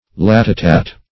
Latitat \Lat"i*tat\, n. [L., he lies hid.] (O. Eng. Law)